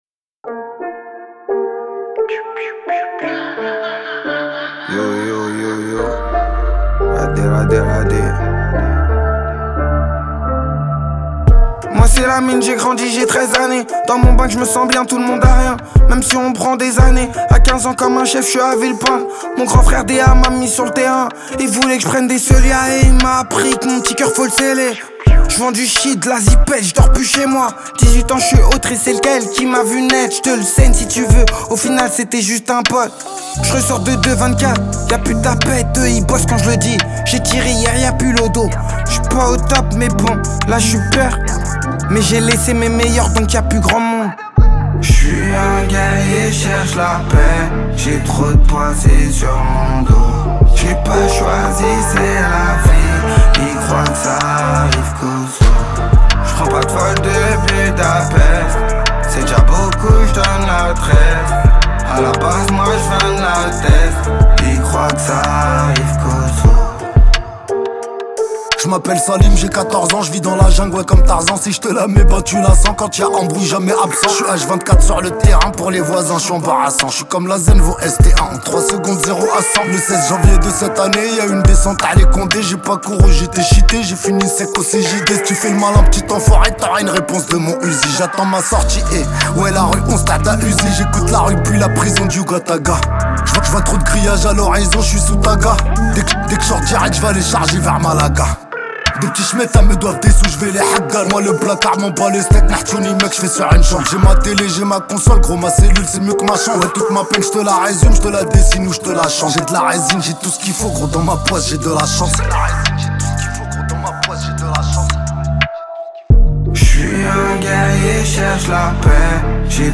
0/100 Genres : raï Écouter sur Spotify